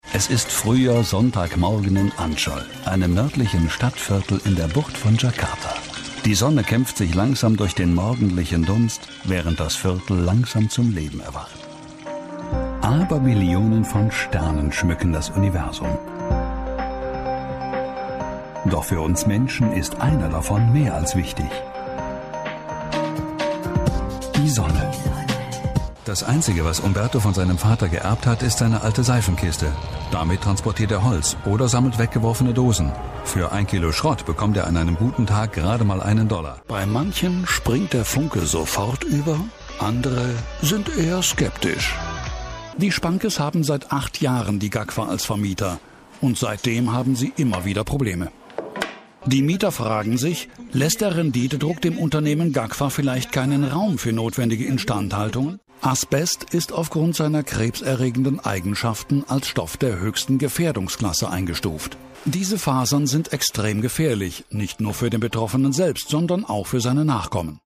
Off-Stimme/TV/Dokumentation (Sprachcollage)